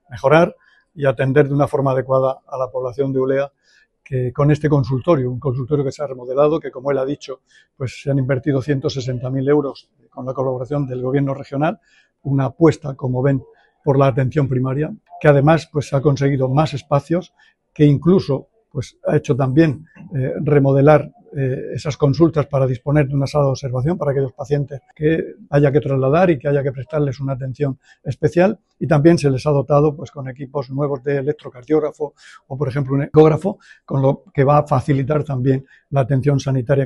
Sonido/ Declaraciones del consejero de salud, Juan José Pedreño, en su visita al nuevo consultorio de Ulea.